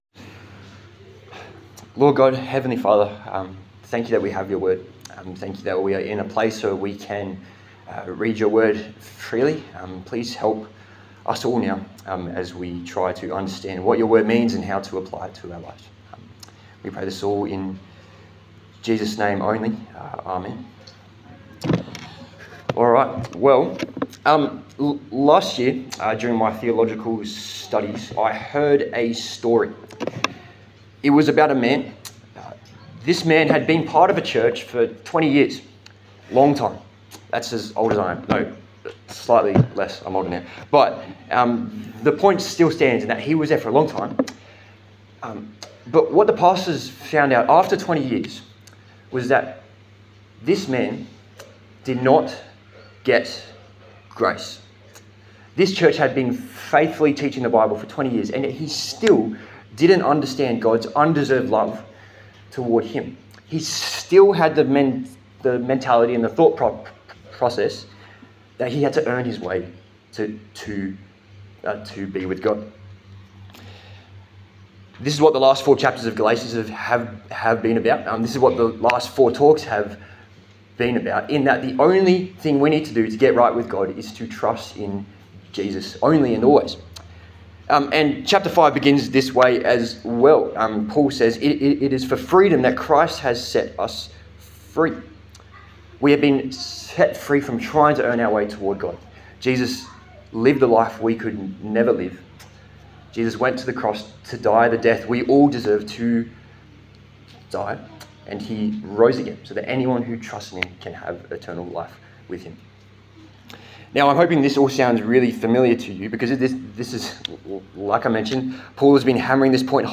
Service Type: Sunday Service A sermon in the series on the book of Galatians